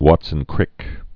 (wätsən-krĭk)